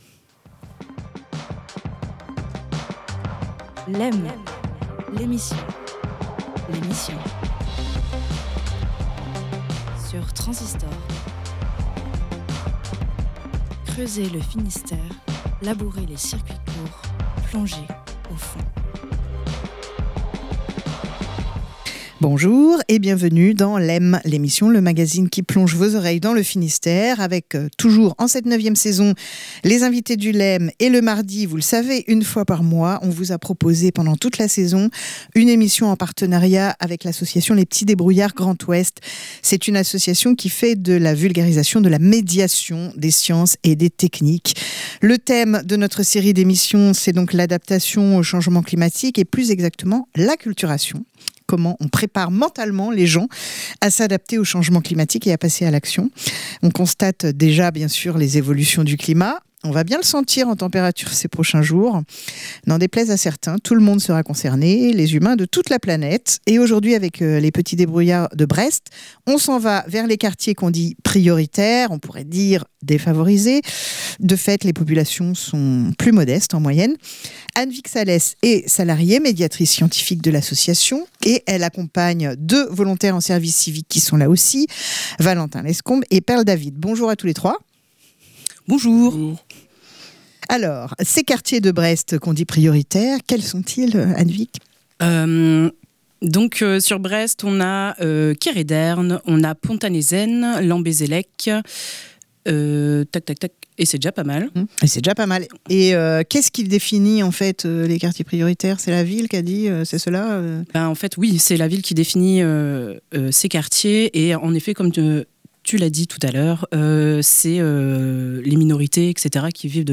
Avec les Petits débrouillards Grand Ouest de l'antenne de Brest, on suit les animations proposées dans les quartiers prioritaires autour de l'acculturation au changement climatique.